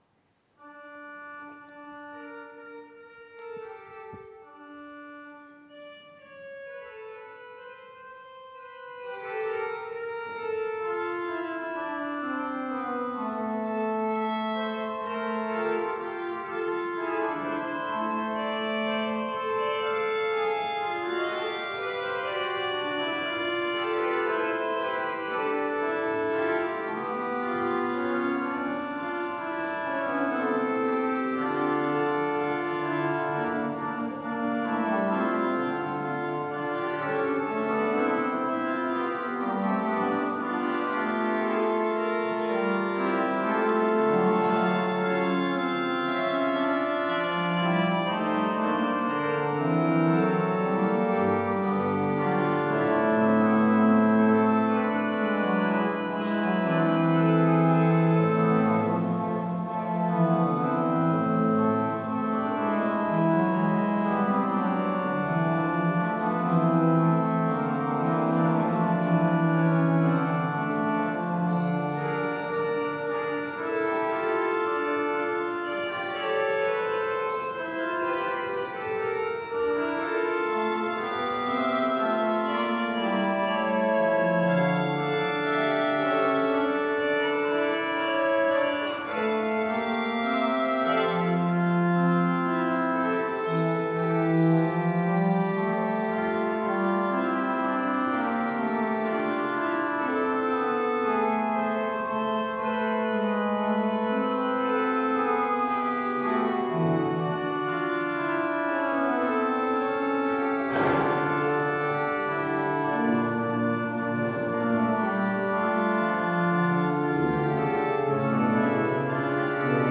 Orgelausgang
Audiomitschnitt unseres Gottesdienstes am 16.Sonntag nach Trinitatis 2024